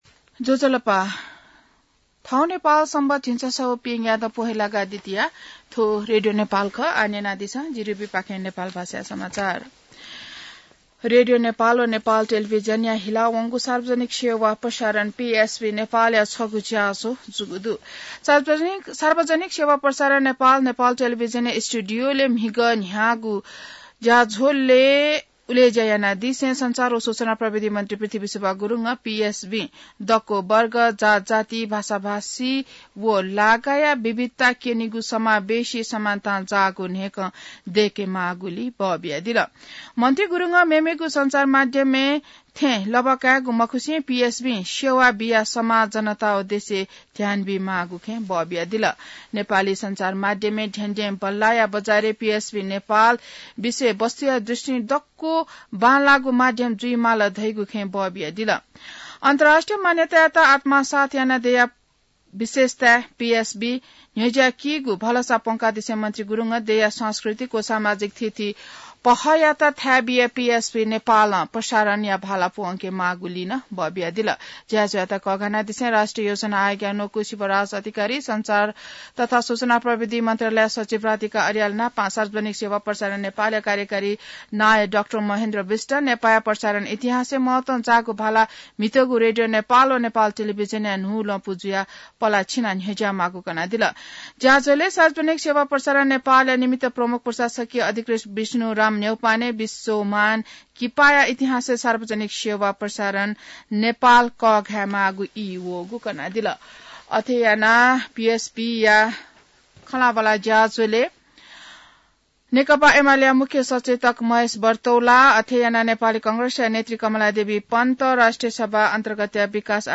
नेपाल भाषामा समाचार : ३ माघ , २०८१